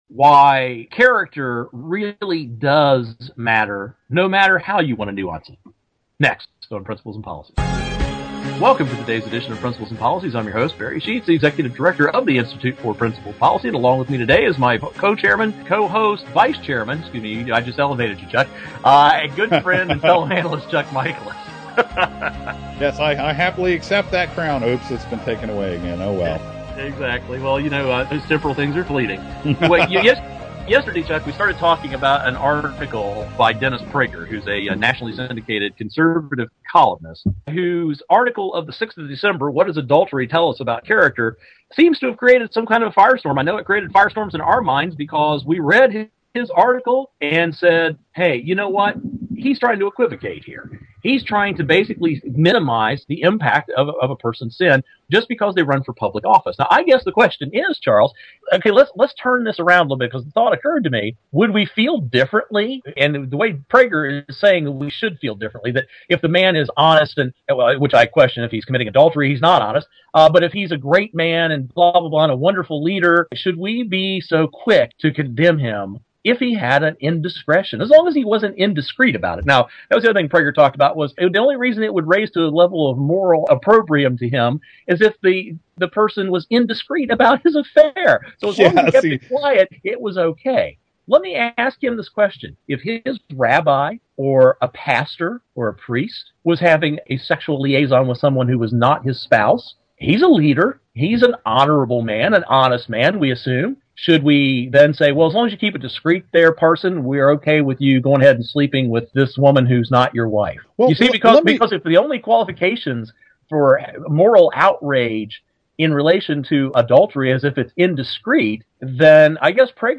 Our Principles and Policies radio show for Friday December 16, 2011.